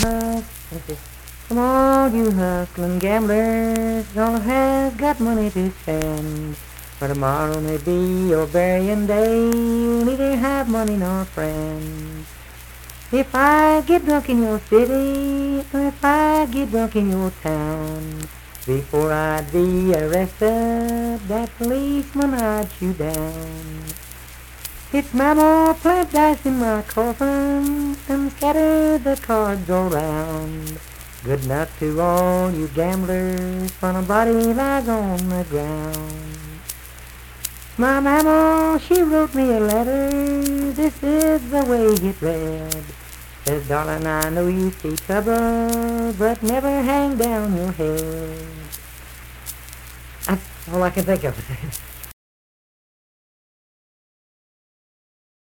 Unaccompanied vocal music performance
Verse-refrain 3(4) & R(4).
Voice (sung)